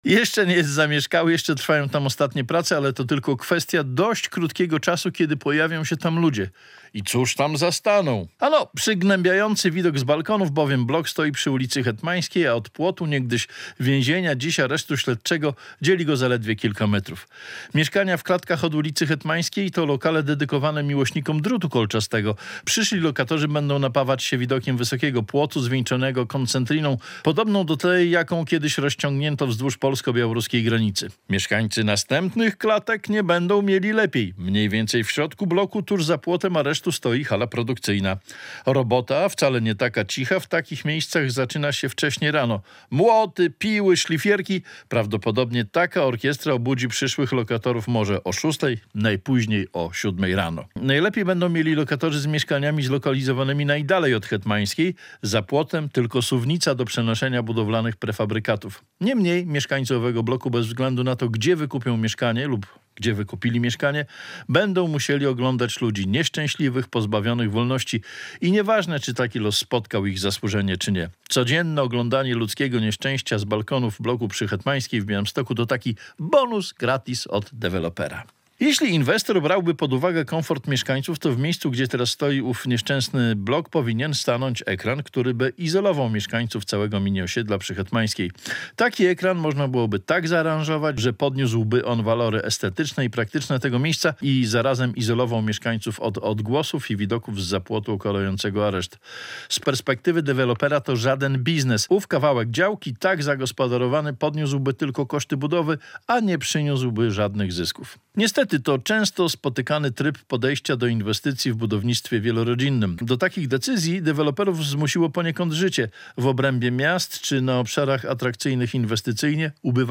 Balkon z widokiem na areszt - felieton